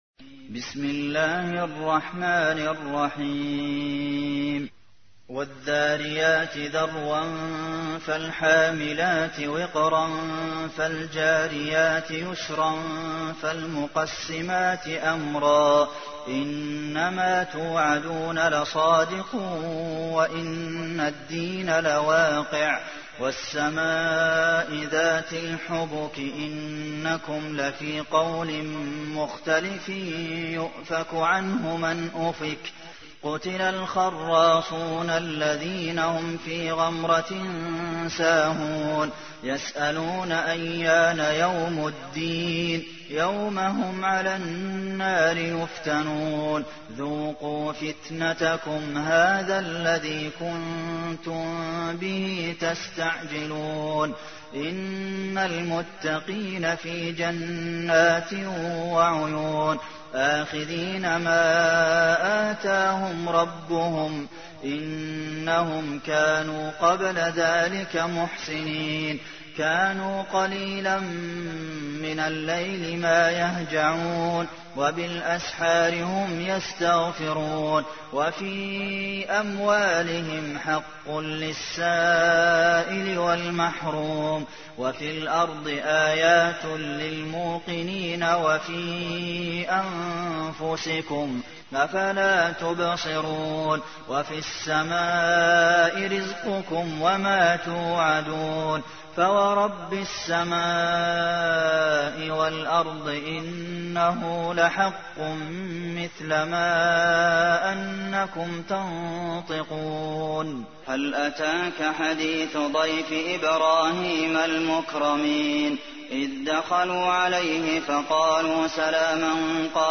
تحميل : 51. سورة الذاريات / القارئ عبد المحسن قاسم / القرآن الكريم / موقع يا حسين